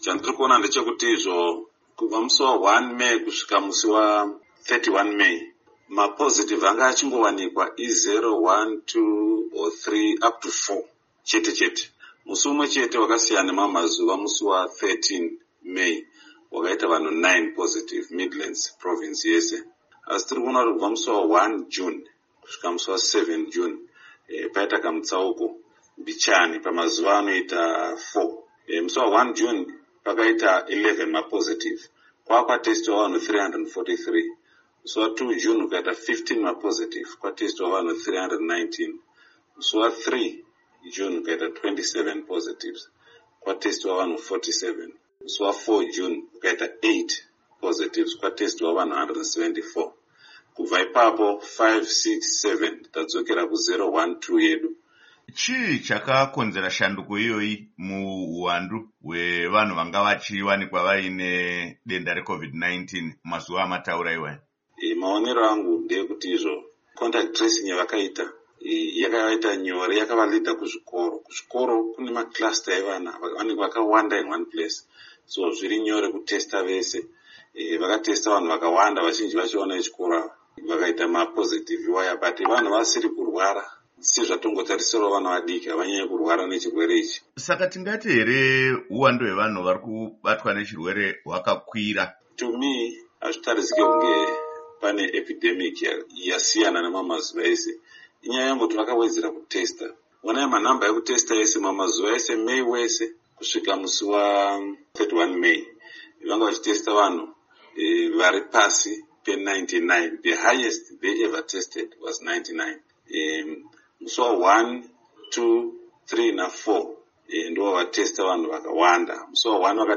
Hurukuro naDr Henry Madzorera